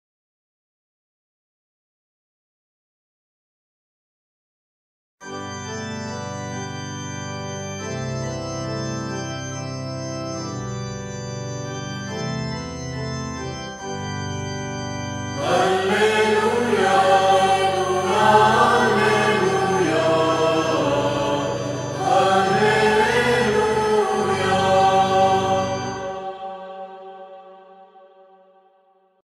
87 هللويا(2)، (لحن غريغوري)